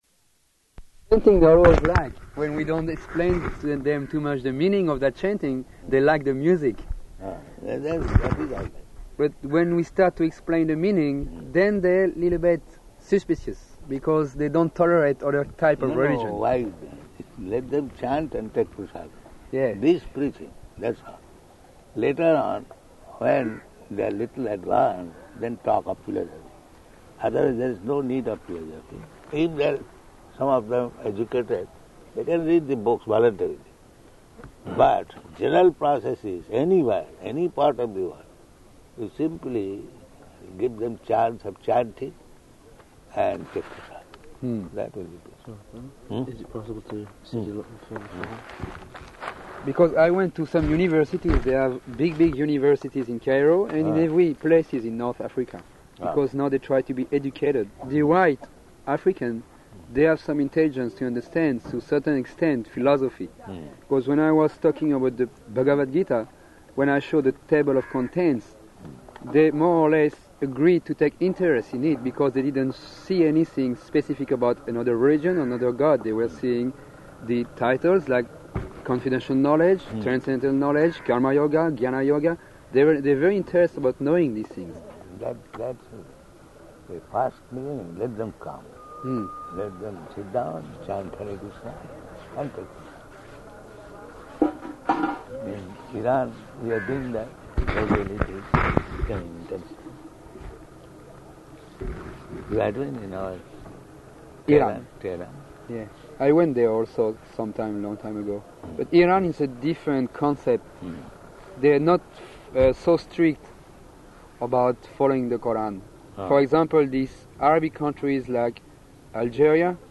Room Conversation
Type: Conversation
Location: Bhubaneswar